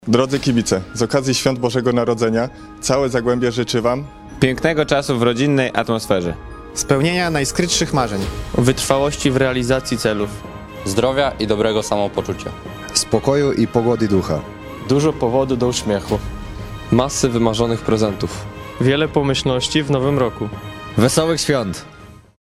Życzenia od piłkarzy KGHM Zagłębia – audio